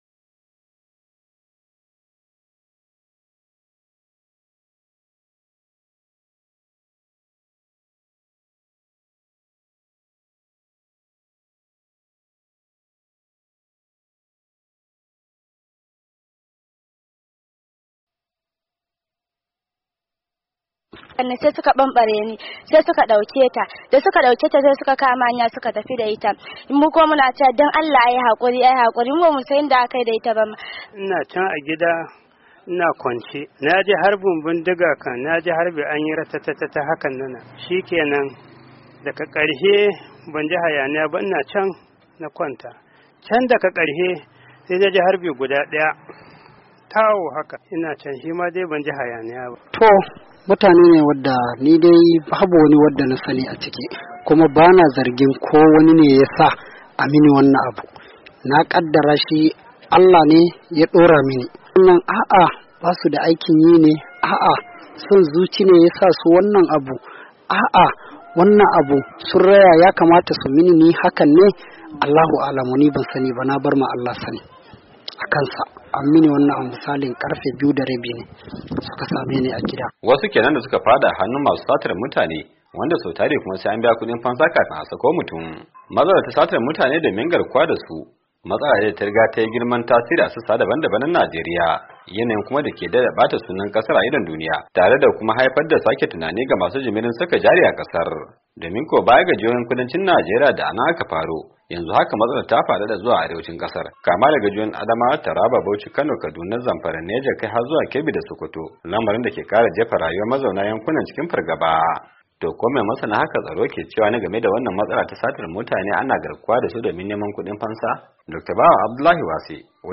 Ga cikakken rahoton.